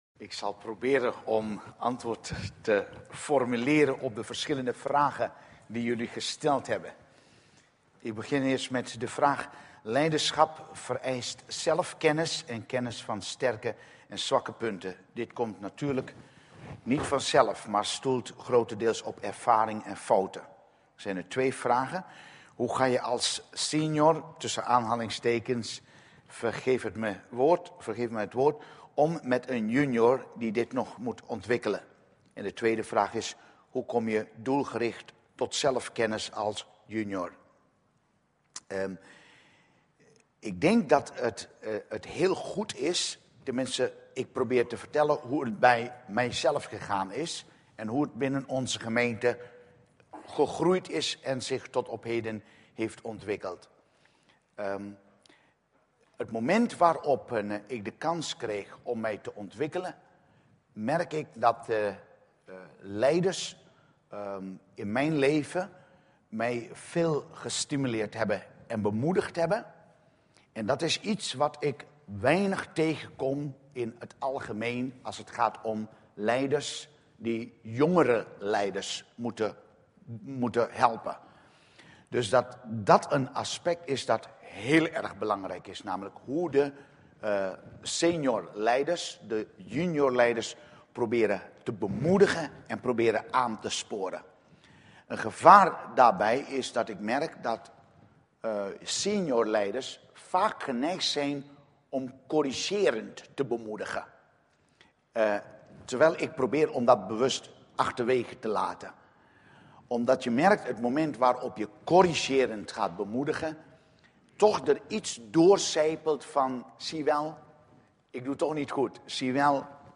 Dienstsoort: Studiedag